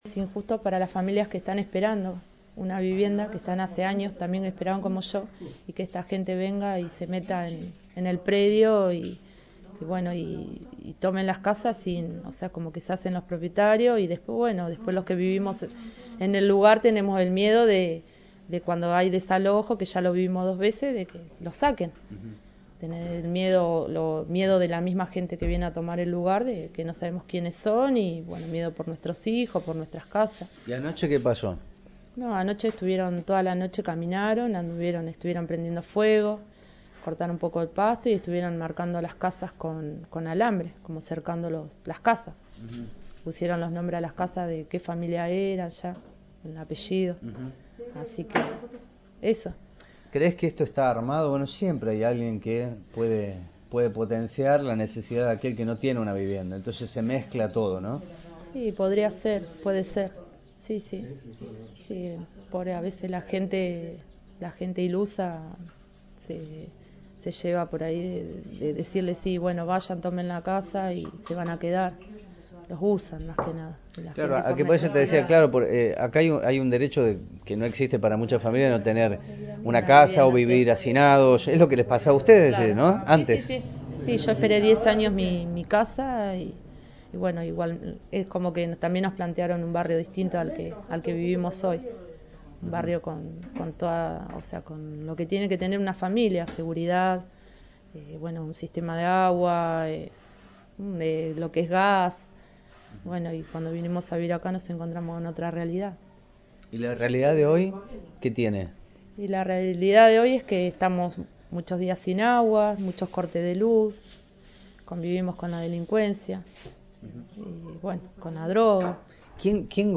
AUDIO 2 VECINA